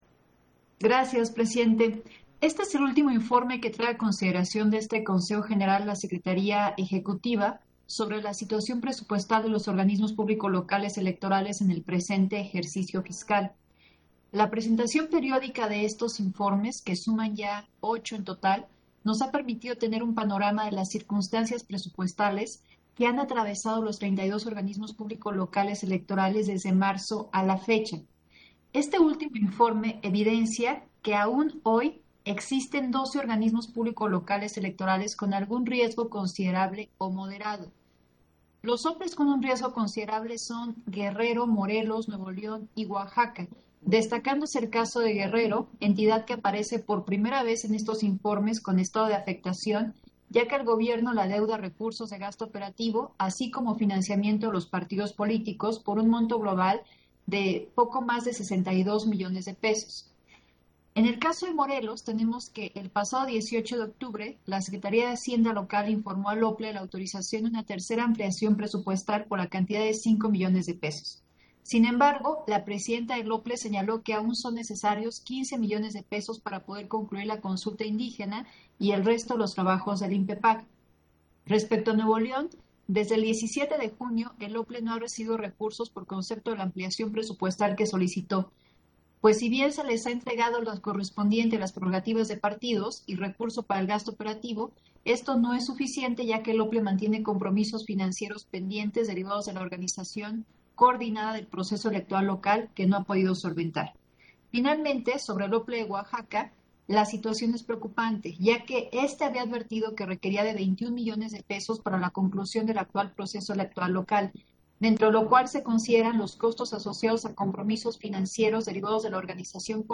Intervención de Dania Ravel, en Sesión Ordinaria, relativo al informe sobre la situación presupuestal de los Organismos Públicos Locales